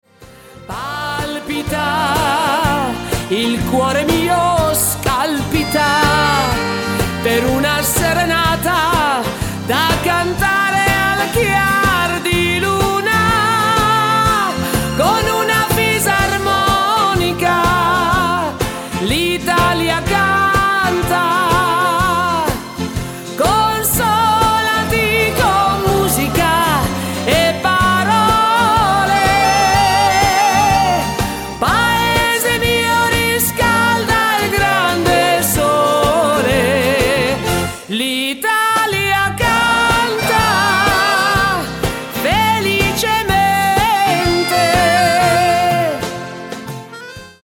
BALLATA  (4.19)